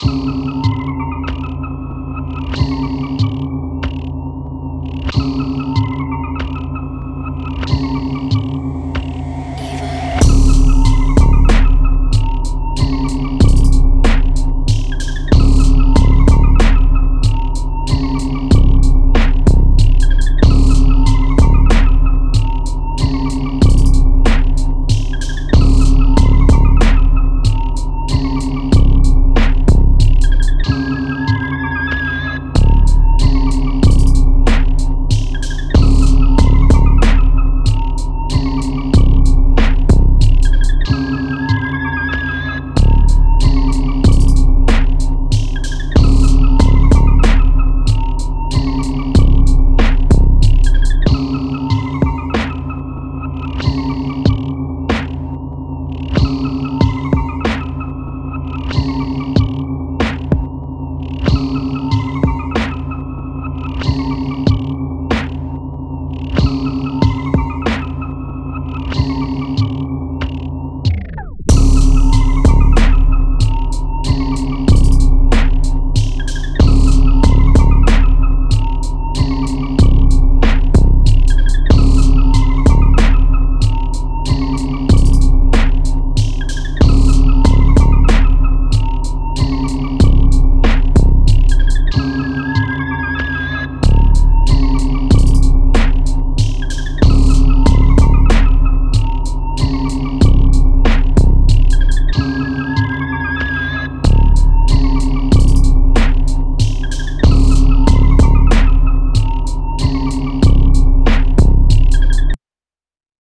Time – (1:53)　bpm.94